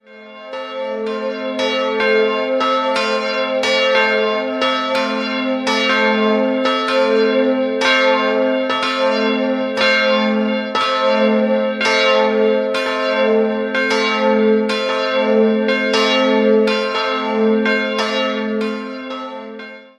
Die beiden Glocken sind in einem schlichten, niedrigen Glockenträger aufgehängt.
Die beiden Glasfenster entwarf der Kunstmaler Distler aus München. 2016 wurde die Kirche entwidmet. 2-stimmiges KleineTerz-Geläute: a'-c'' Die Glocken wurden 1962 von Rudolf Perner in Passau gegossen.